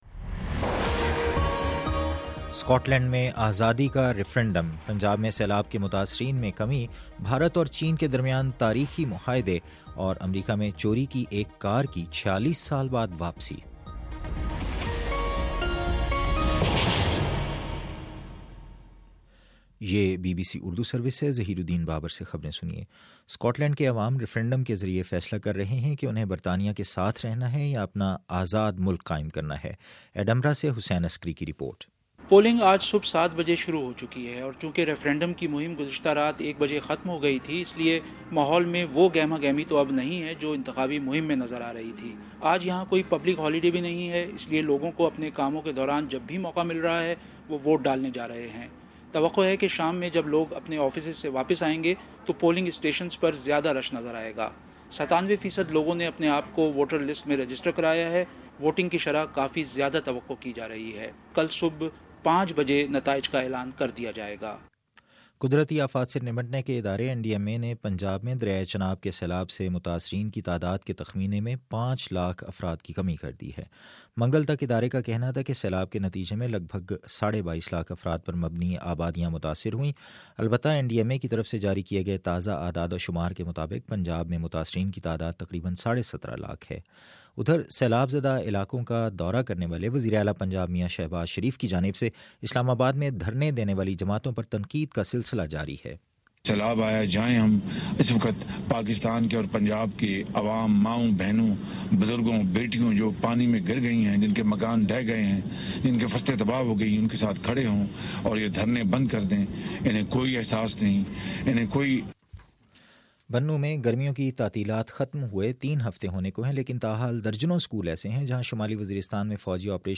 ستمبر18 : شام سات بجے کا نیوز بُلیٹن